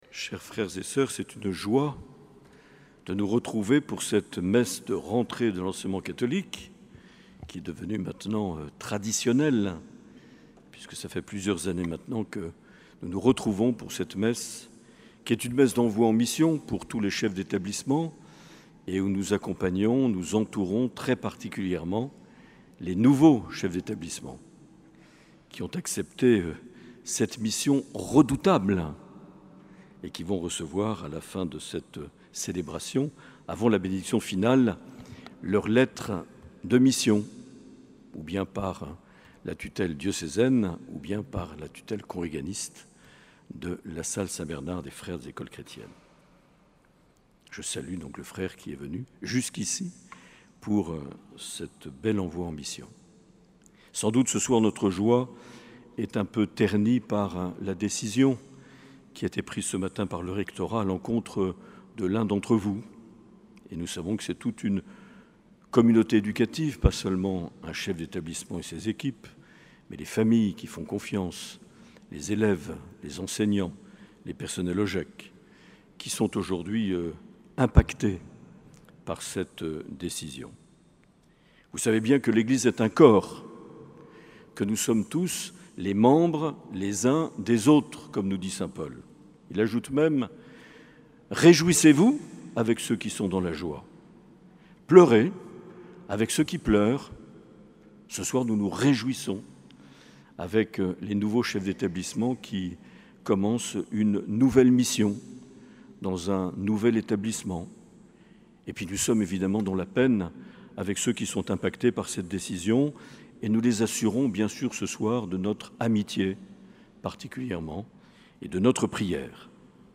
11 septembre 2024 - Messe de rentrée de l’Enseignement Catholique - Cathédrale Sainte-Marie de Bayonne
Accueil \ Emissions \ Vie de l’Eglise \ Evêque \ Les Homélies \ 11 septembre 2024 - Messe de rentrée de l’Enseignement Catholique - (...)